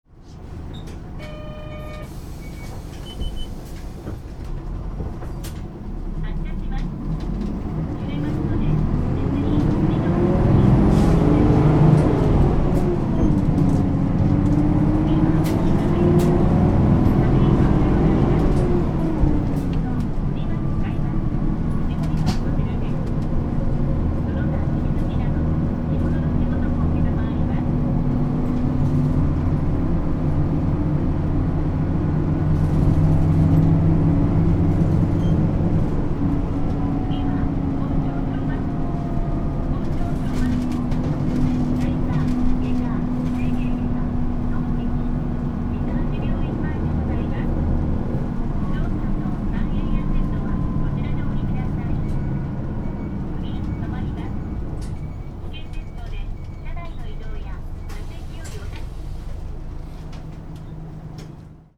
全国路線バス走行音千葉中央バス
こちらはPJ代で、冷房が稼動しており、低音がかなり大きい走行音です。 1203【大高町→誉田駅】 型式：2KG-LR290J3 備考：OD6速，AMT 最新の導入車両となる新型エルガミオ．AMTの自動変速による走行です．アクセルを踏み込むと比較的高回転まで回ります．